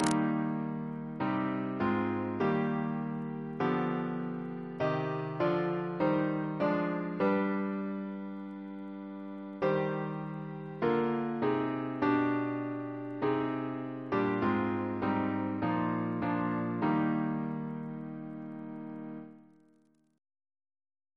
Double chant in E♭ Composer: William Bayley (1810-1858) Reference psalters: ACB: 368; ACP: 257; OCB: 237; PP/SNCB: 108